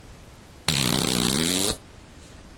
nice fart 1
fart flatulation flatulence gas noise poot weird sound effect free sound royalty free Memes